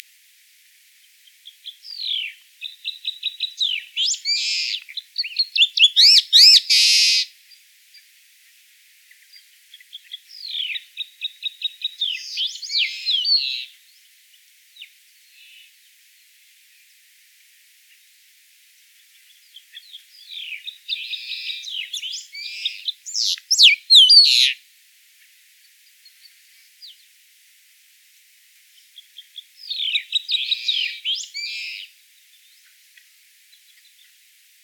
Loica-comun-Leistes-loyca.mp3